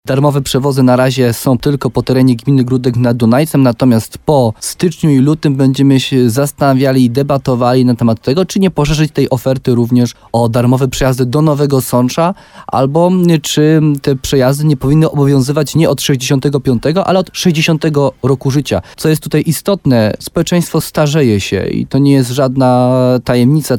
Jak mówił wójt Jarosław Baziak w programie Słowo za Słowo na antenie RDN Nowy Sącz, to na razie pilotażowy program, który ma pokazać, jakie jest zainteresowanie bezpłatnymi przejazdami.